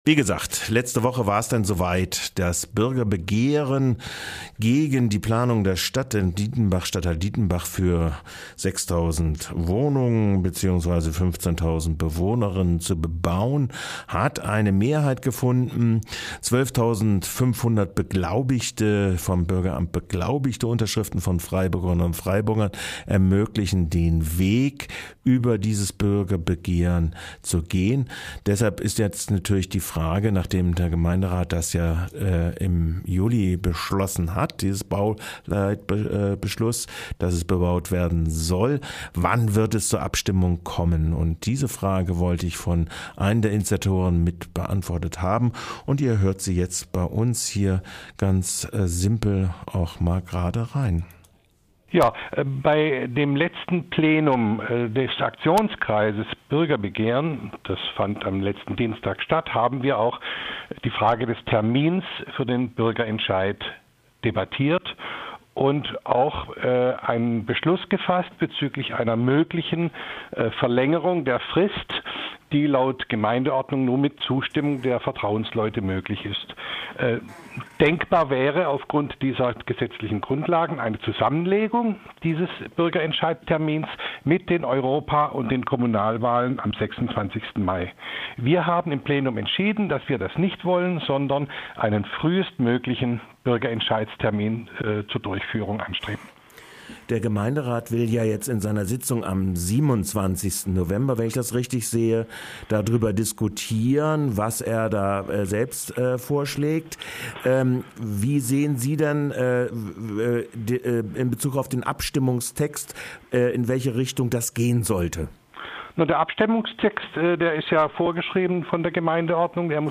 Nachrichten
08.02.2023 Atai Keller Stadtrat und Urgestein (neudeutsch: Kulturmanager) von Freiburgs Freier Kultuszene fragte und der EBM Ulrich von Kirchbach Antwortete im Kulturauschuß om 7.2.23 antwortete: 1:00